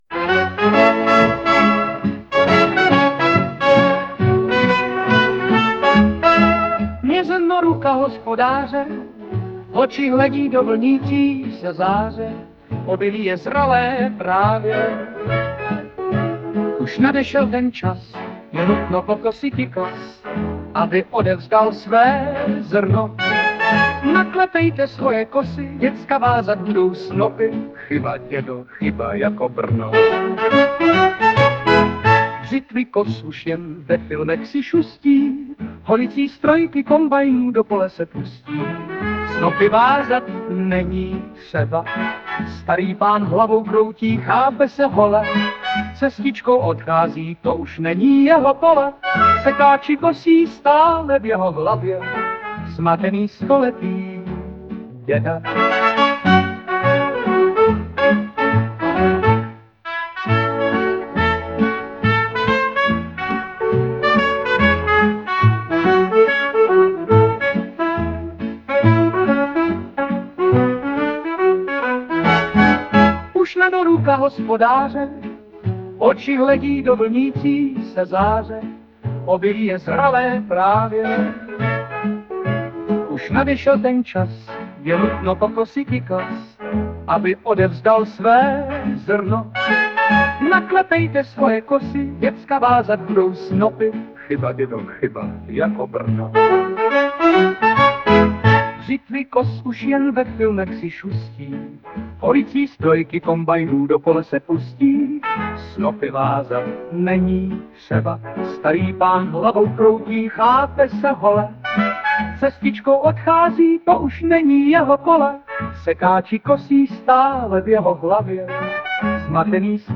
* hudba, zpěv: AI
Tohle je fakt "pamětnickej" styl, povedlo se to...a teda klobouk dolů, není tak lehký, trefit to:-)*